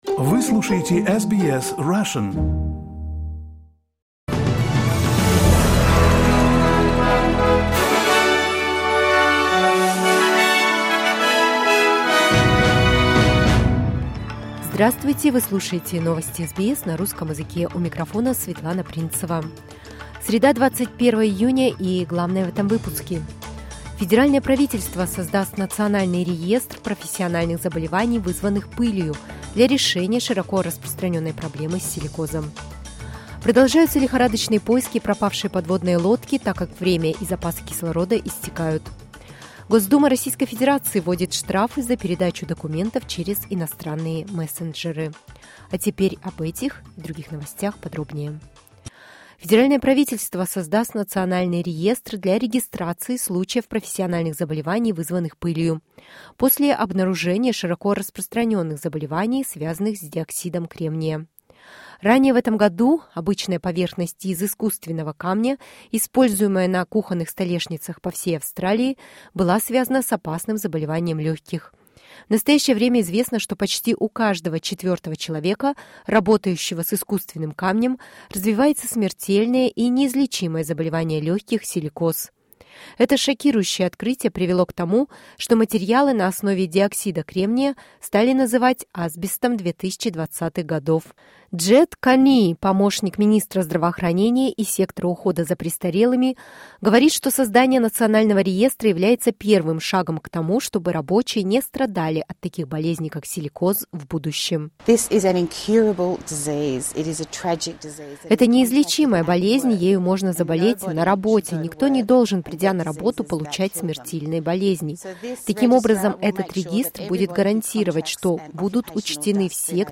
SBS news in Russian — 21.06.2023